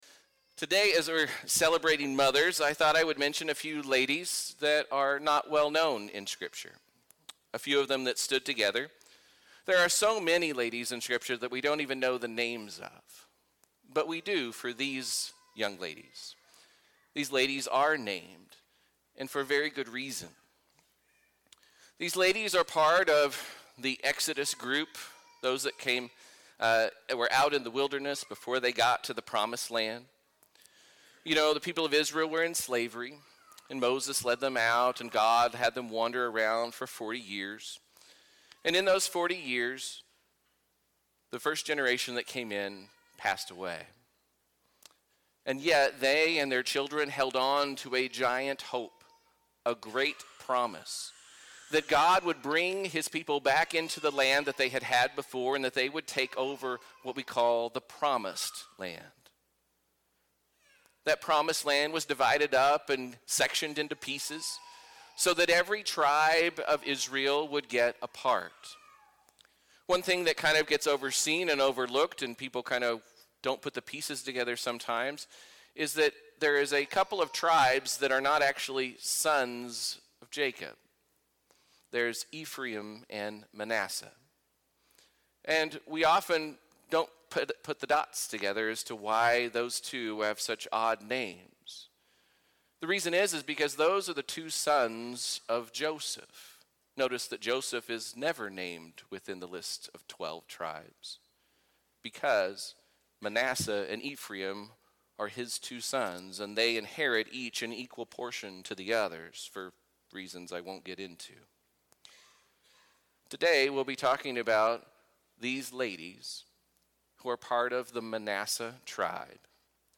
shares a Mother's Day message about how daughters sought to carry on their family legacy.